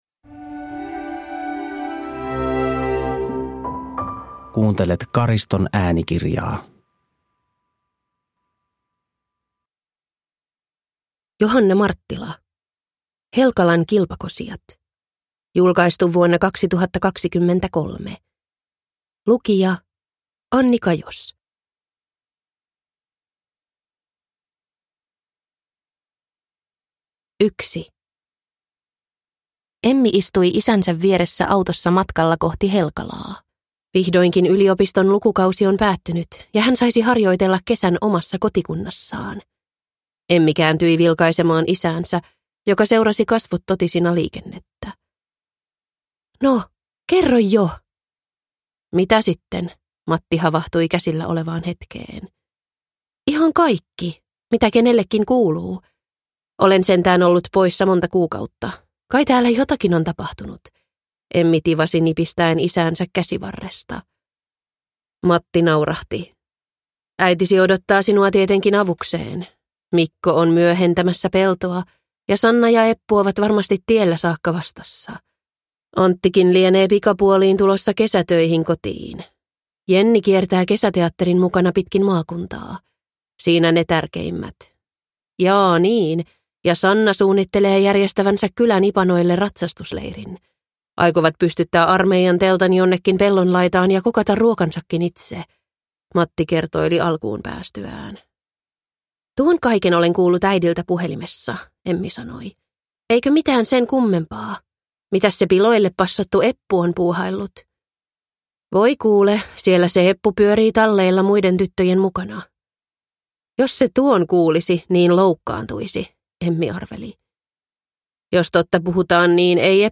Helkalan kilpakosijat – Ljudbok – Laddas ner